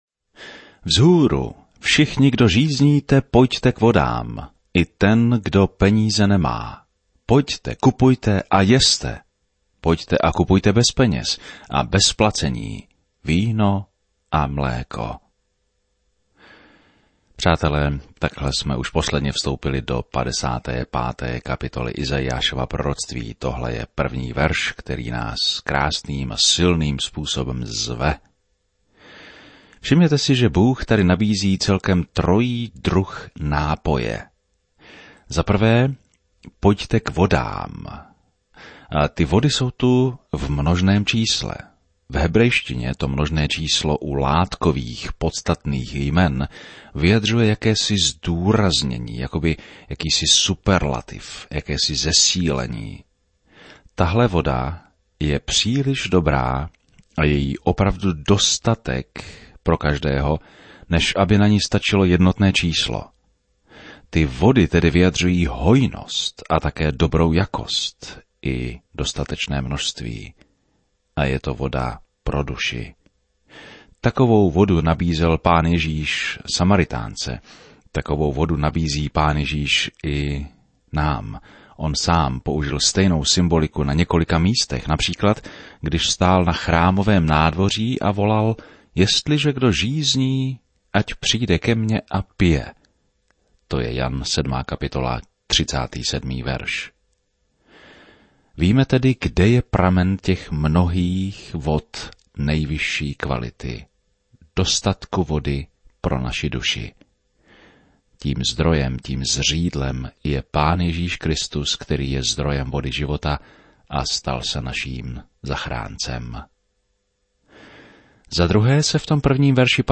Je zamýšlen jako každodenní 30ti minutový rozhlasový pořad, který systematicky provádí posluchače celou Biblí.